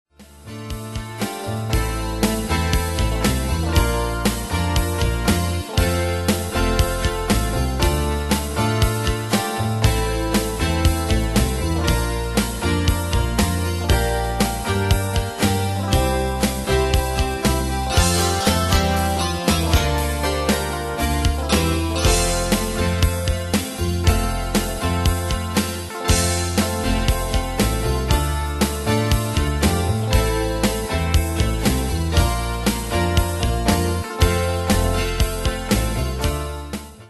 Style: Pop Année/Year: 1996 Tempo: 118 Durée/Time: 3.08
Danse/Dance: PopRock Cat Id.
Pro Backing Tracks